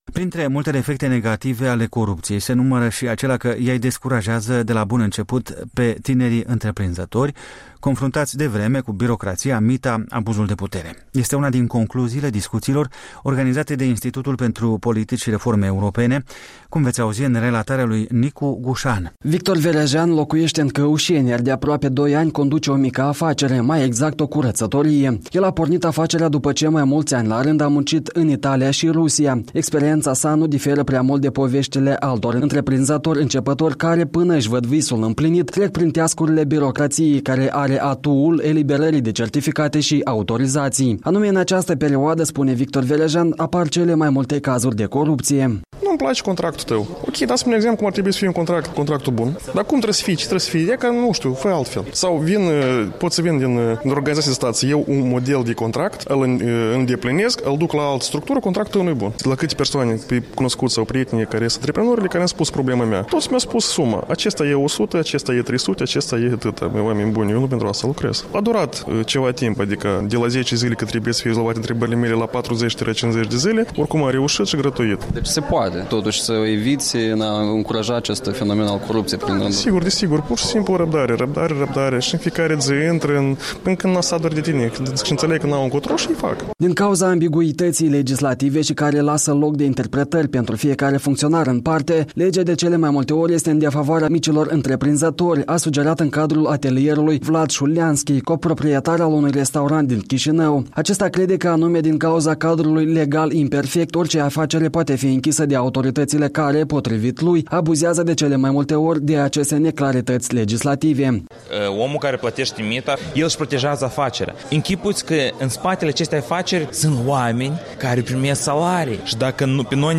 Opinii la un atelier de discuții despre micii întreprinzători și corupția birocrației de stat moldovene,